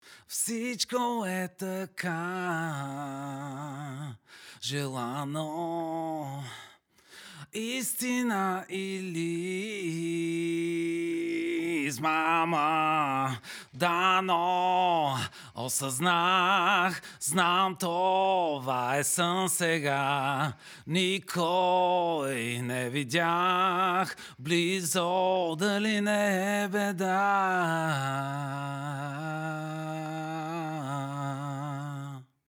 Стъпка 5 - запис на вокали
Самото помещение няма нищо откъм третиране и т.н. (просто в репетиционната), без "вокални кабинки" и други спецЯлни дивотии.
Един лек бъг - използвахме полузатворени слушалки и метрономът се хващаше учудващо прецизно, но с два масивни notch EQ хода успях да елиминирам 95% от кликалката без това да нарани записаното.
Ето кратко демо на полусуров вокален трак:
UnrealVocalDemo.mp3